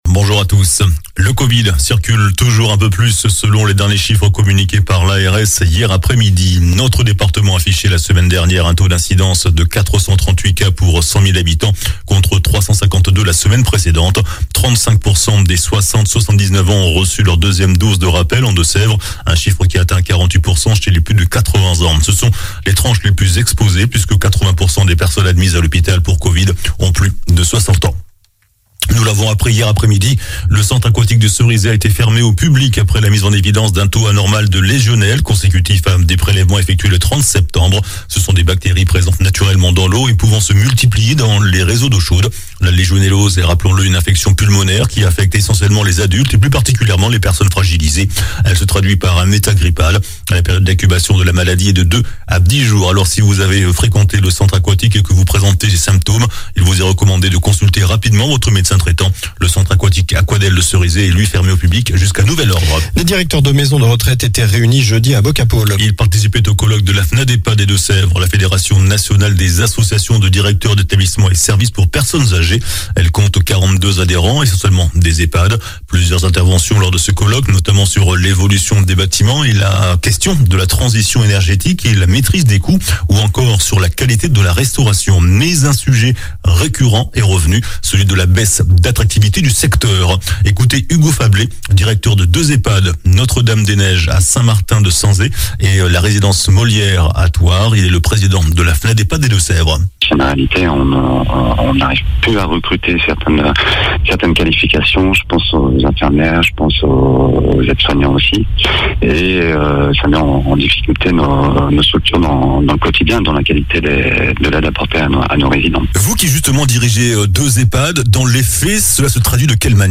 JOURNAL DU SAMEDI 08 OCTOBRE